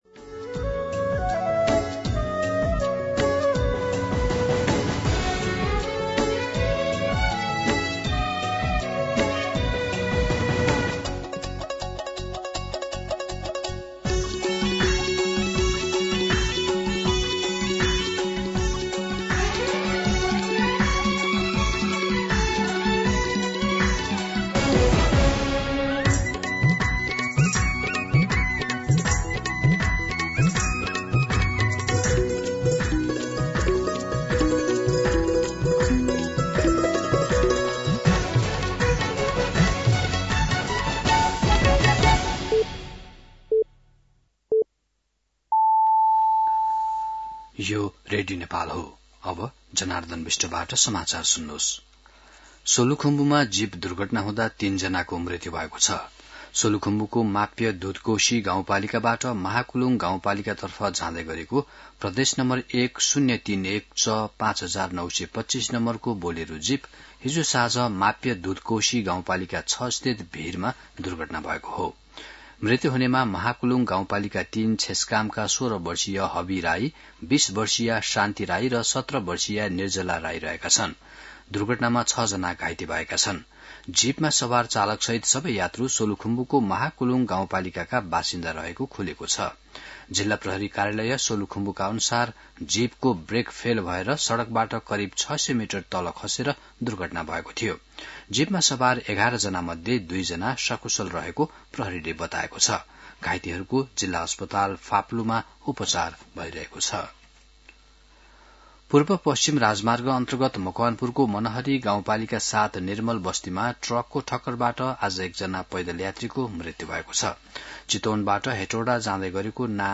मध्यान्ह १२ बजेको नेपाली समाचार : ७ कार्तिक , २०८२
12-pm-Nepali-News-8.mp3